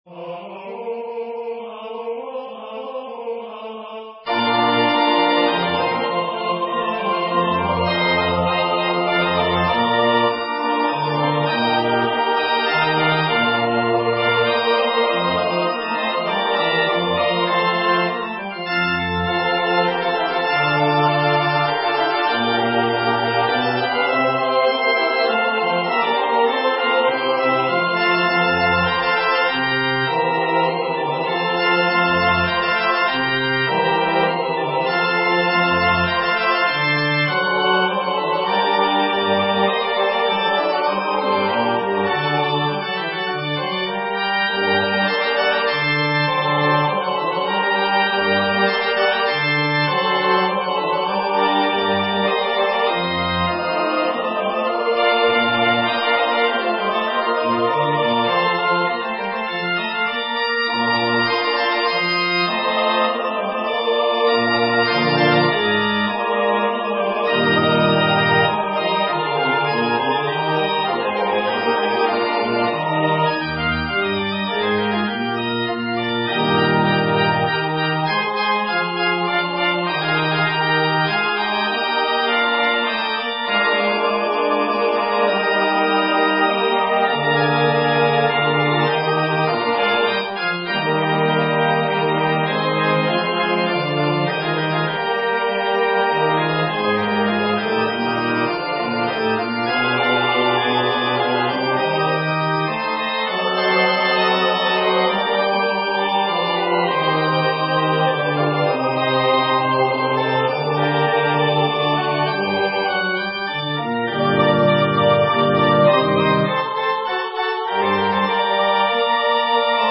Number of voices: 4vv   Voicing: SATB
Genre: SacredMass
Instruments: Organ